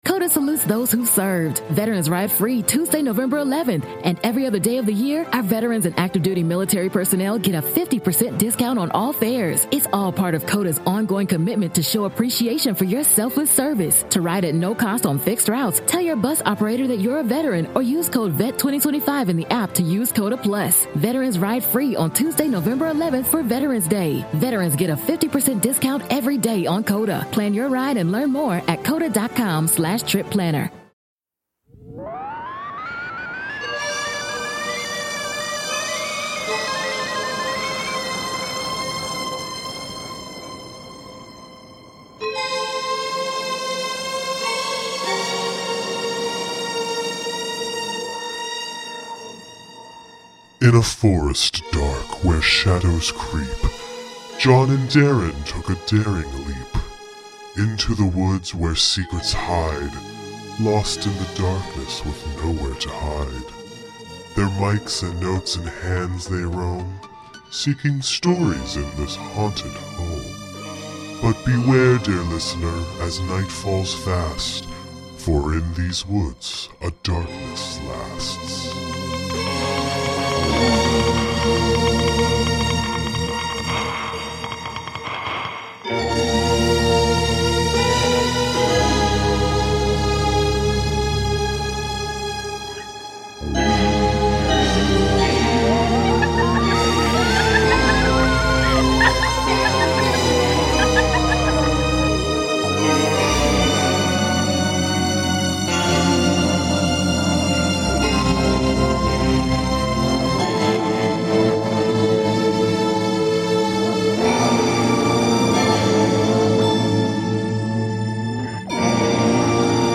This audio drama will play like an old-fashioned radio program, complete with sound effects, music, and surprises! Please consider listening with headphones for an ideal audio experience.